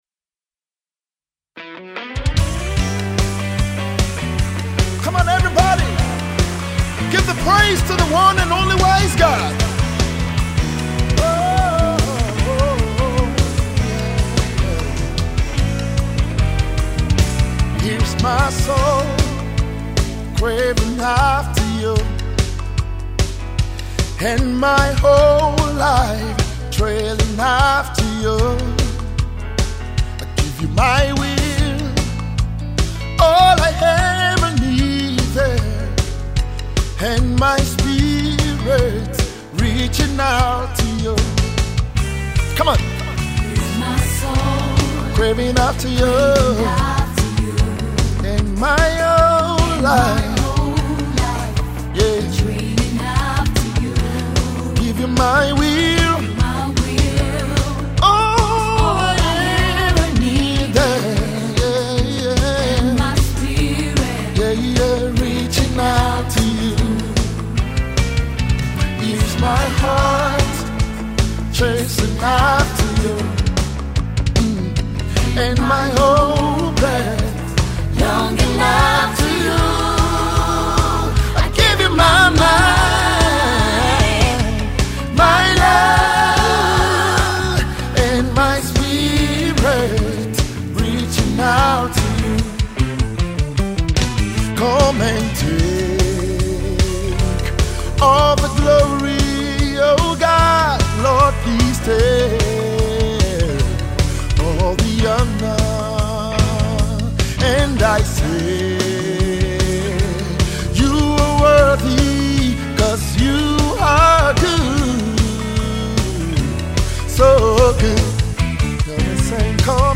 Dynamic Gospel music minister